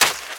STEPS Sand, Run 21.wav